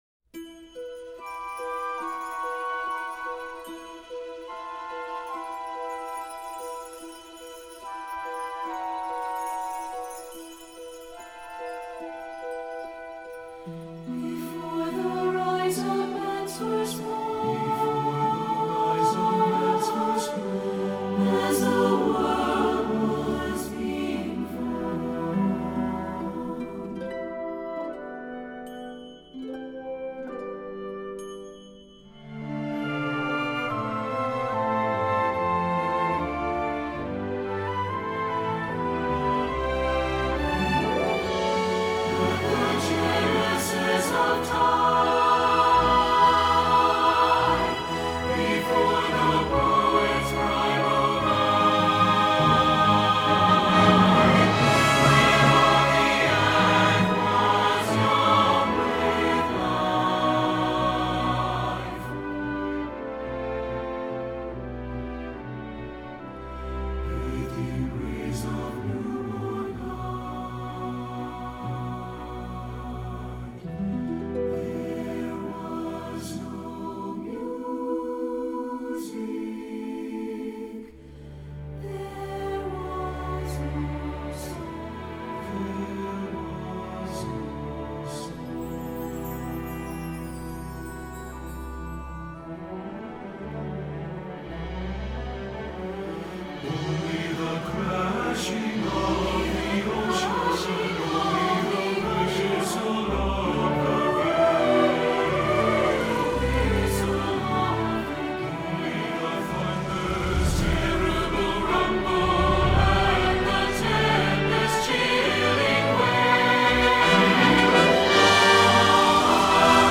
Voicing: SATB divisi and Piano